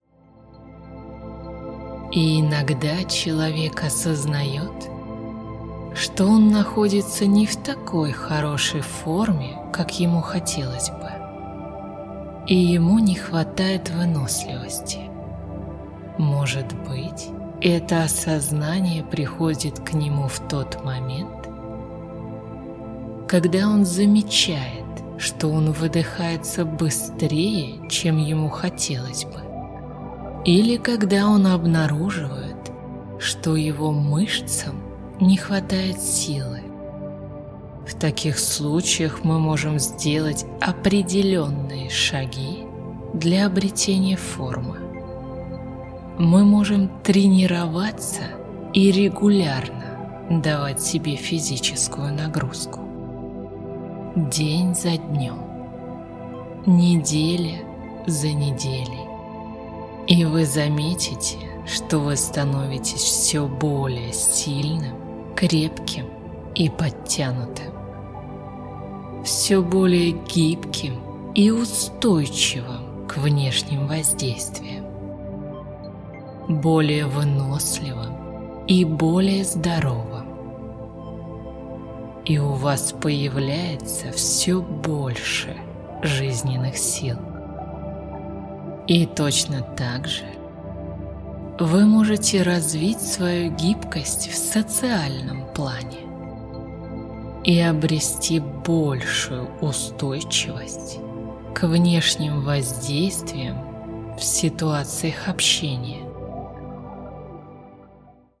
Вторая часть — это мощный аудиосеанс гипноза, который поможет вам навсегда освободиться от страха осуждения.